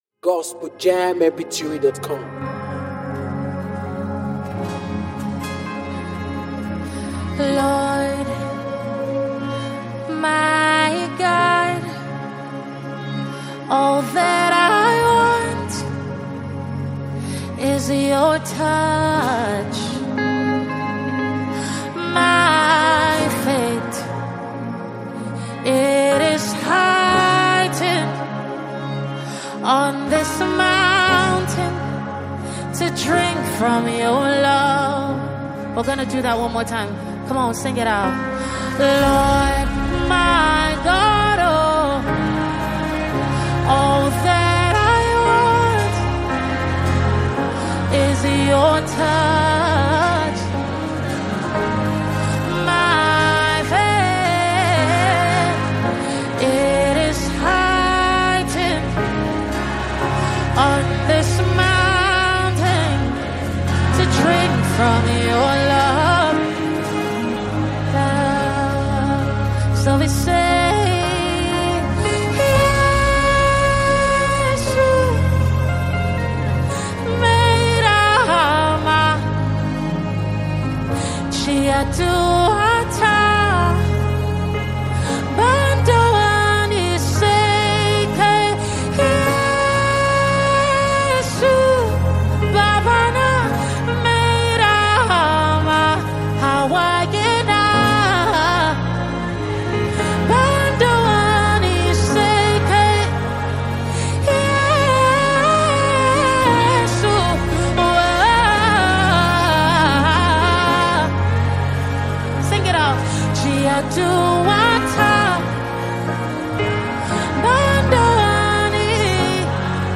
soul-stirring gospel worship song